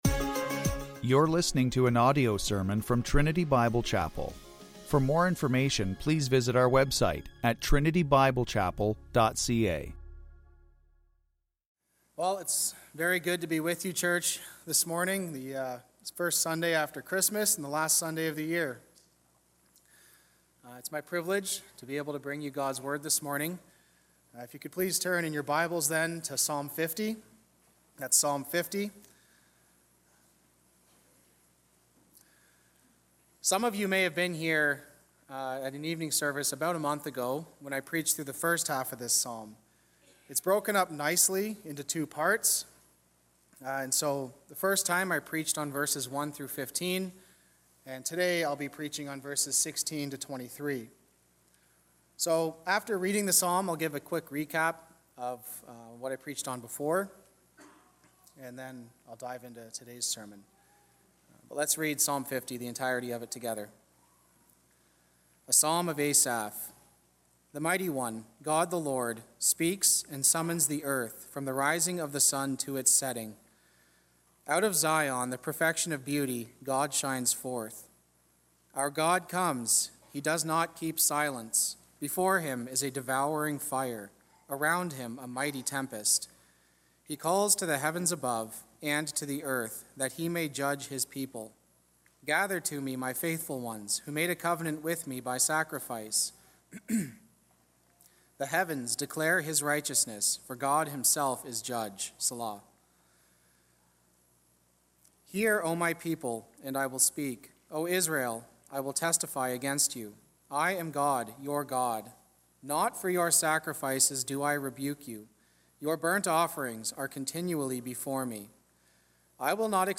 Single Sermons Topics: hypocrisy Audio